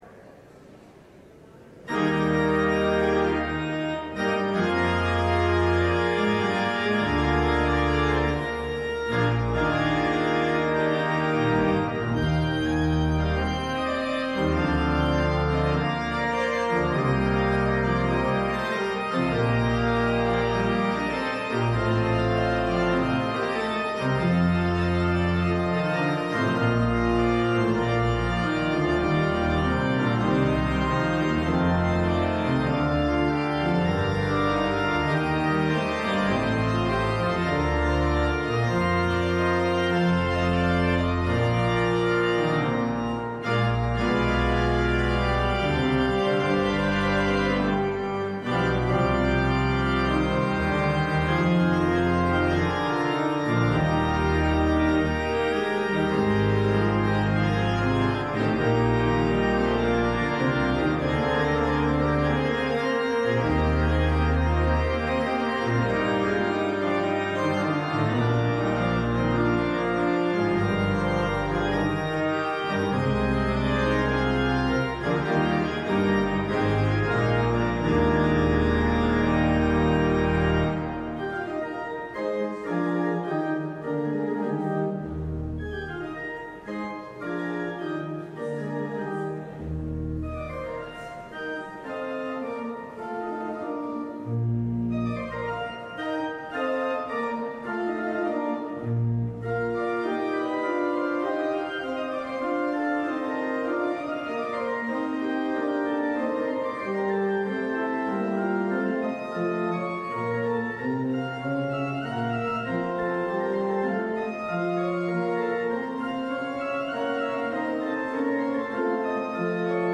LIVE Morning Worship Service - The Prophets and the Kings: The Death of Ahab